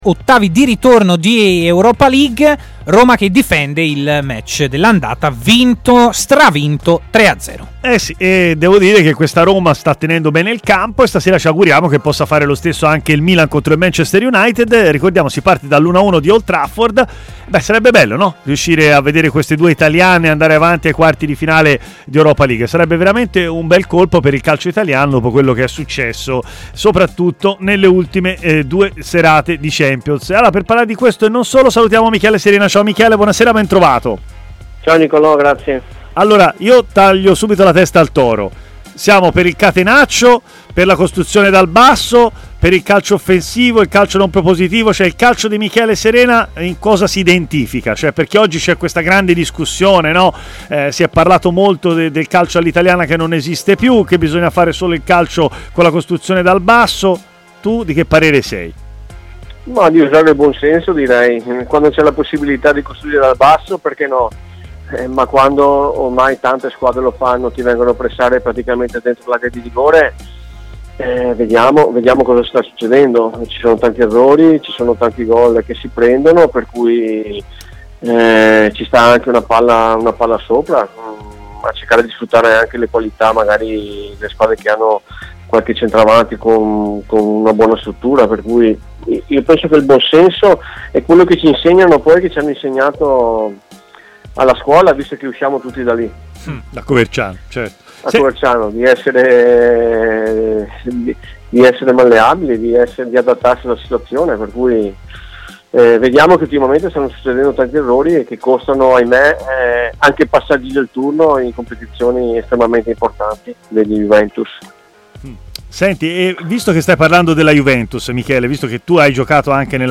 ha parlato in diretta ai microfoni di TMW Radio